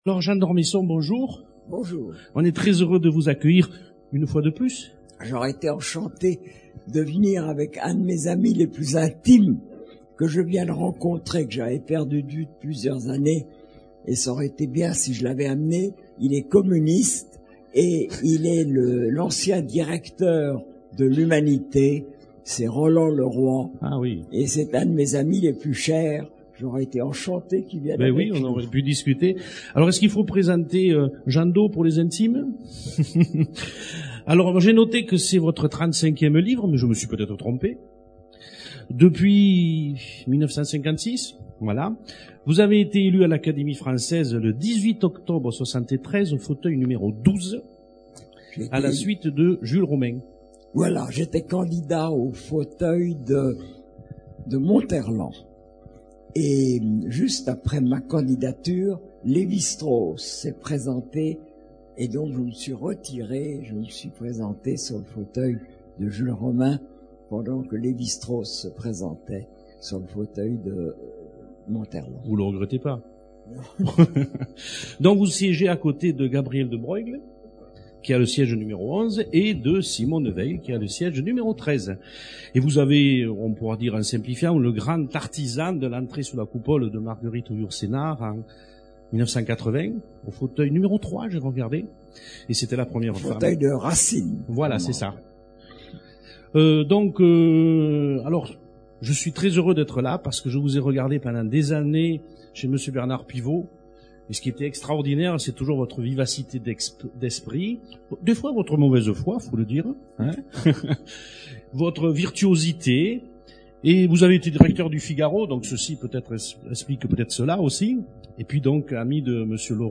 Ormesson, Jean d'. Personne interviewée
Rencontre littéraire